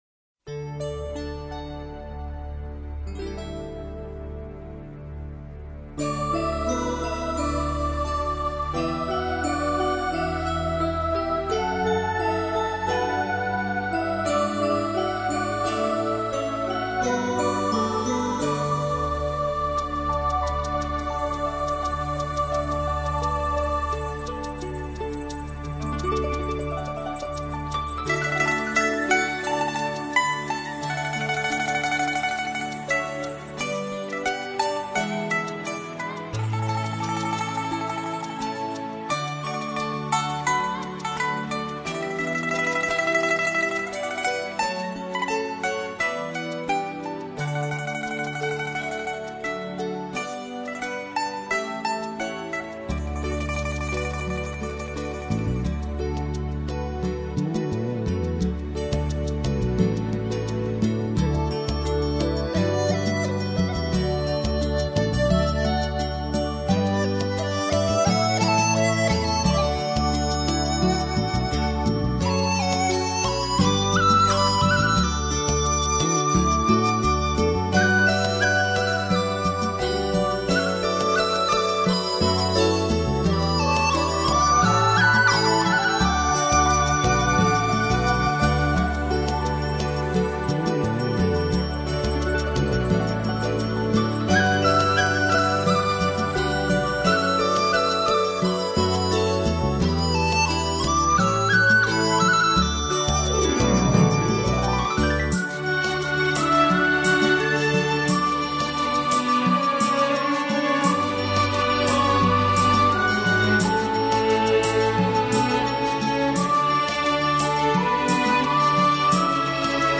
Complete 10 CD Collection Of Relaxation Music